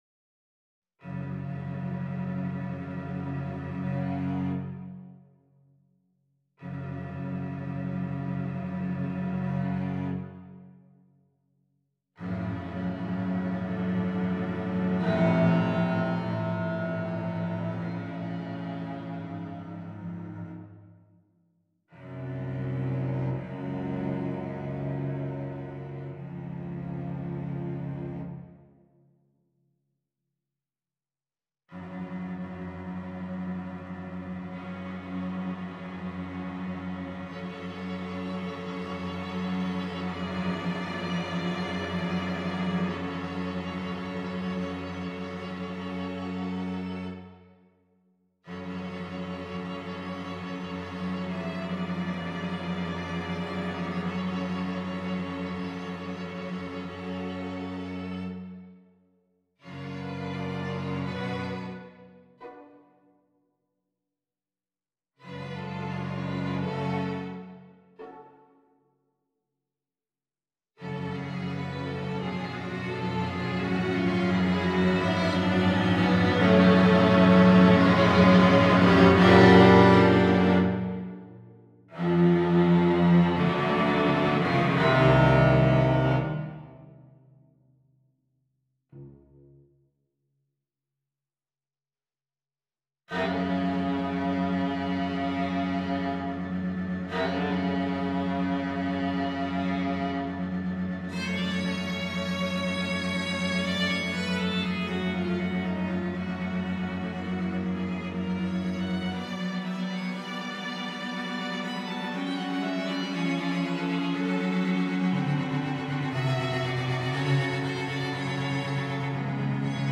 The movement is divided into three parts: Lamentoso, Fugue a6 and the Return.